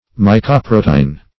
Search Result for " mycoprotein" : The Collaborative International Dictionary of English v.0.48: Mycoprotein \My`co*pro"te*in\, n. [Gr.